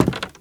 High Quality Footsteps
STEPS Wood, Creaky, Walk 27.wav